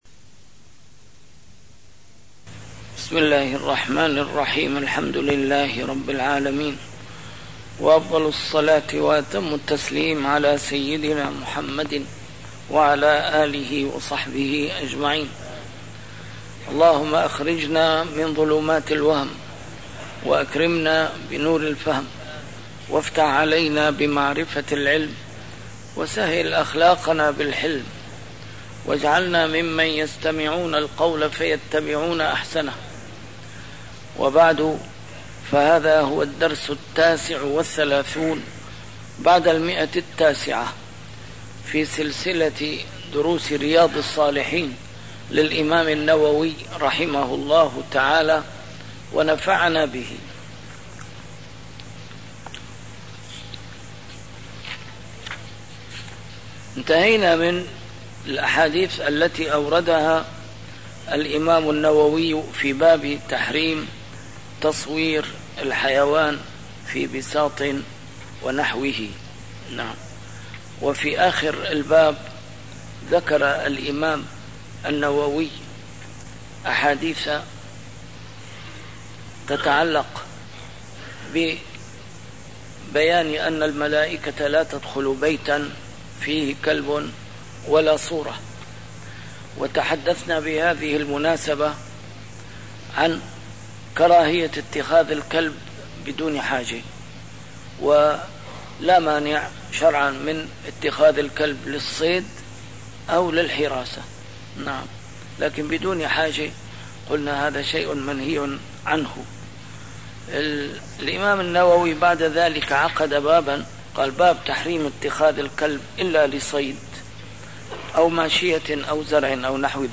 A MARTYR SCHOLAR: IMAM MUHAMMAD SAEED RAMADAN AL-BOUTI - الدروس العلمية - شرح كتاب رياض الصالحين - 939- شرح رياض الصالحين: تحريم اتخاذ الكلب إلا لصيد أو ماشية أو زرع - كراهة تعليق الجرس